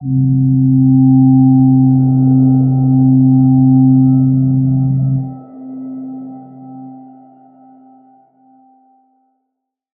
G_Crystal-C4-f.wav